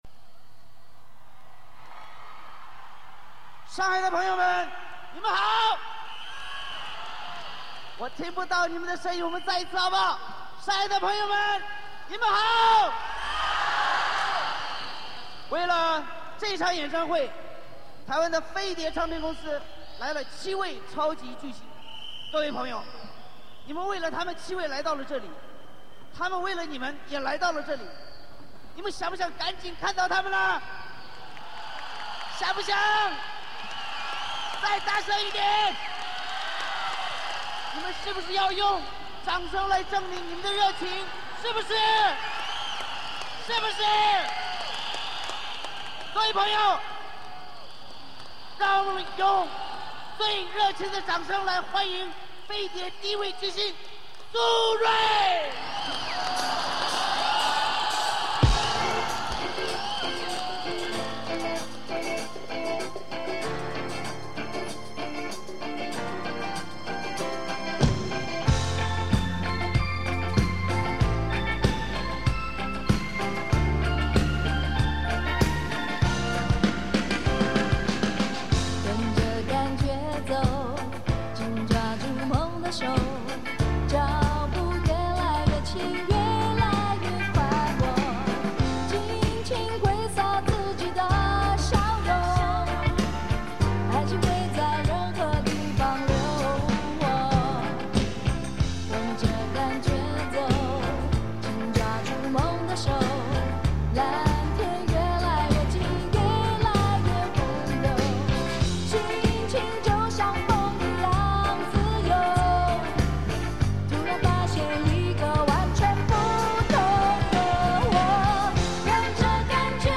演唱会全部曲目